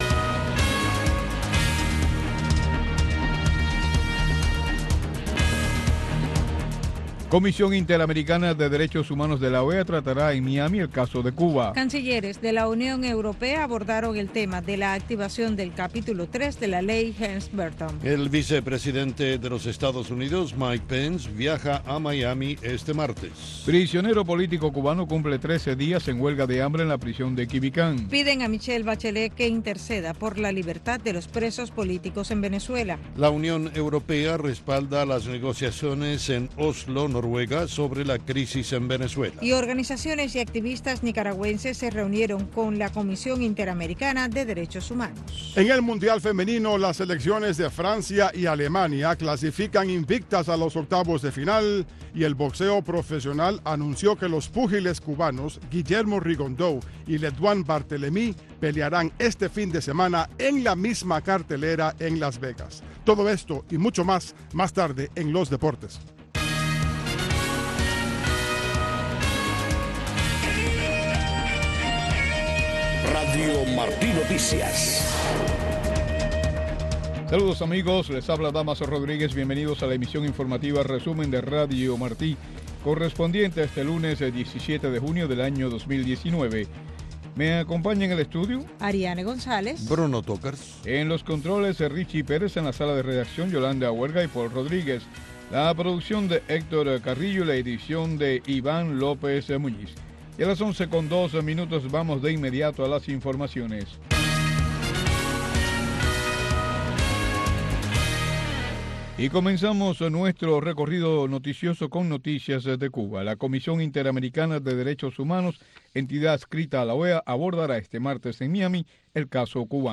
Noticiero de Radio Martí 11:00 PM